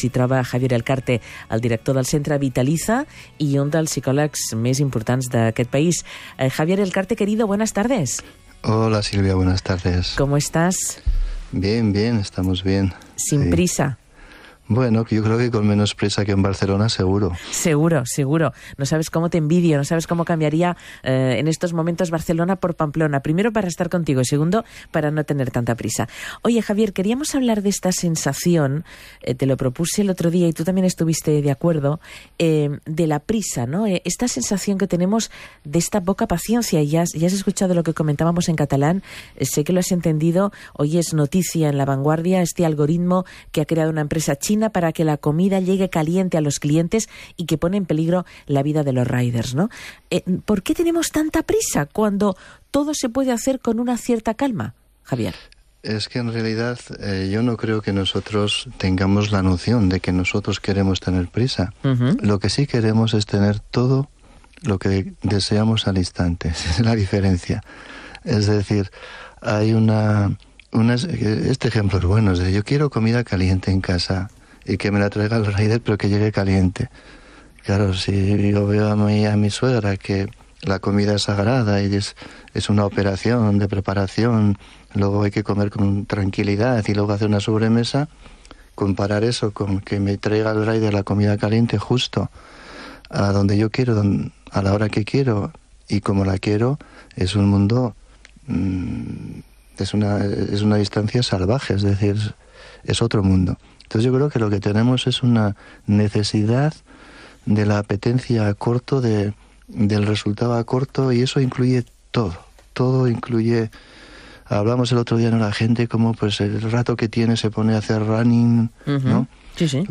Para escuchar la entrevista en castellano al completo, pincha en el siguiente enlace: